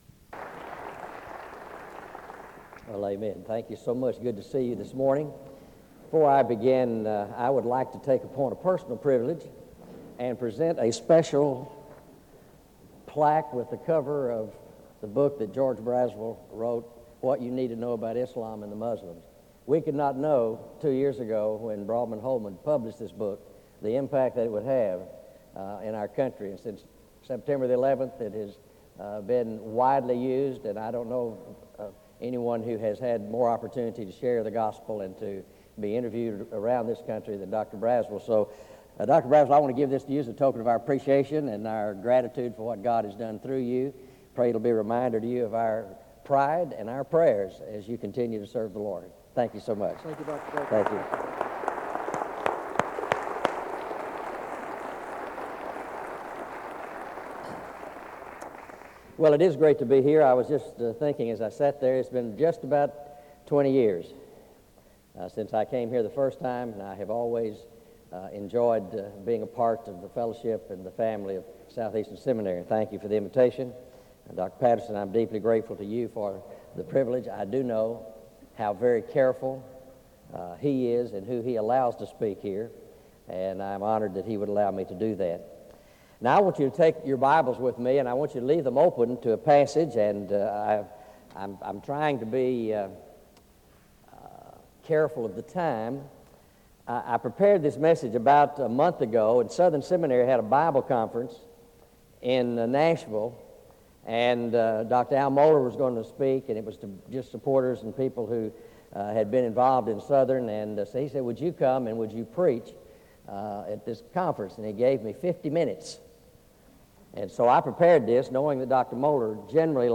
SEBTS Spring Conference
Collection: SEBTS Chapel and Special Event Recordings - 2000s